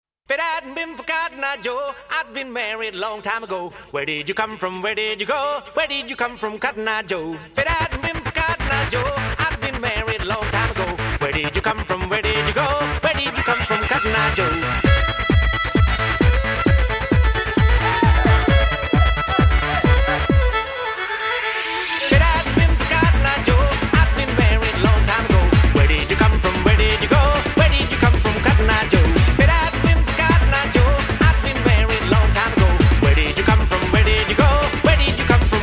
remix version